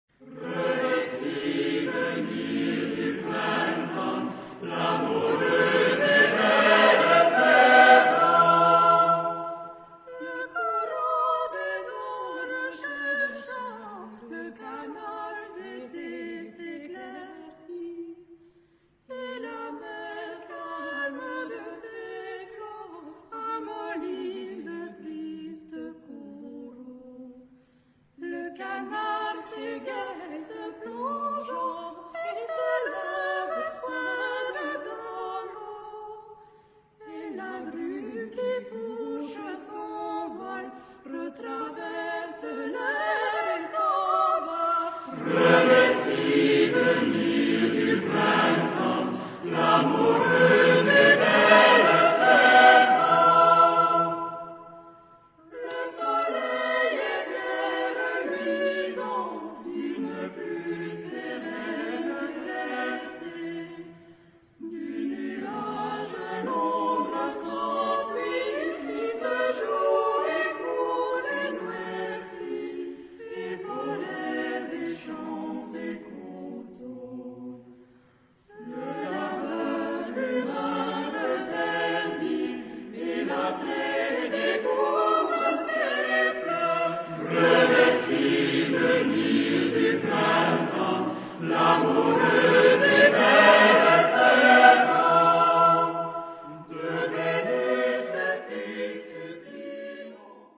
... vers mesurés à l'antique ...
Genre-Style-Forme : Chanson ; Renaissance
Type de choeur : SSATB  (5 voix mixtes )
Tonalité : fa majeur